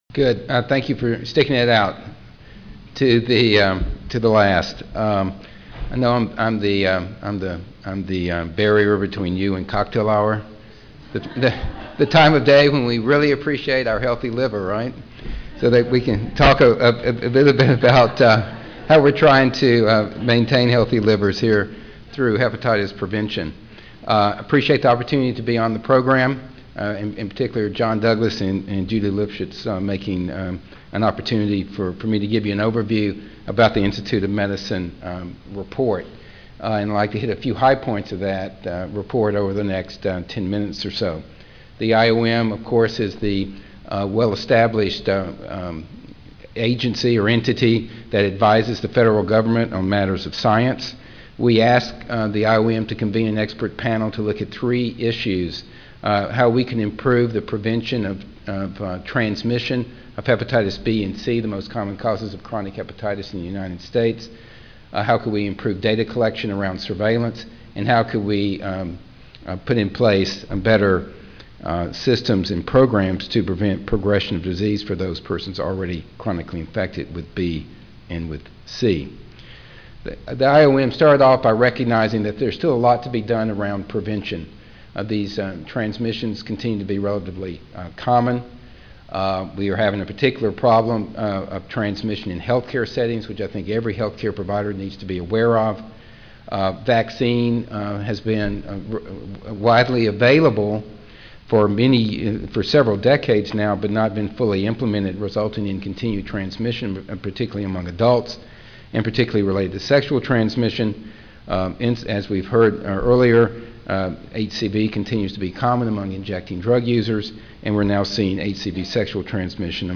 GA Audio File Recorded presentation